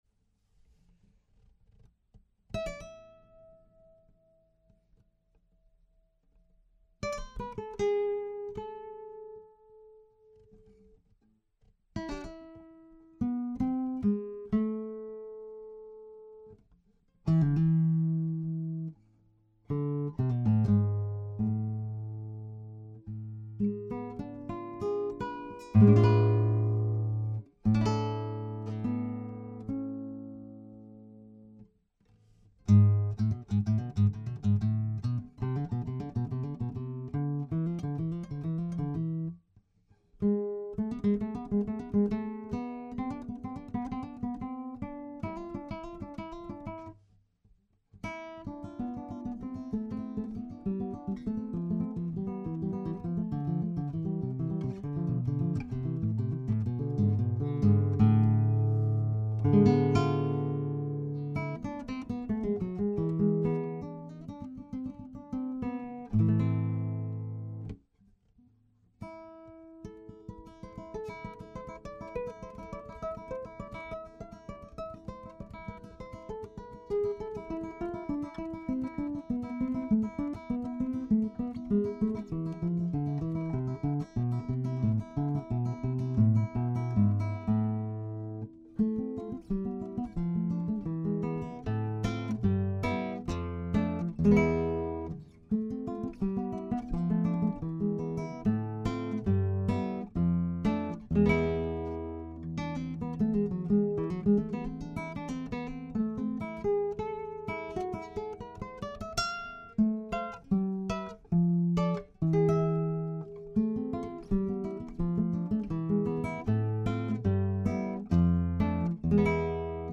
Prodipe GL 21 clip on mic
The mic requires phantom power and on that recording it is plugged into a MOTU M4.
The trio use acoustic guitars and a thing called an "Oud" The Prodipe is really a very close tie tack mic for putting acoustic instruments through a PA.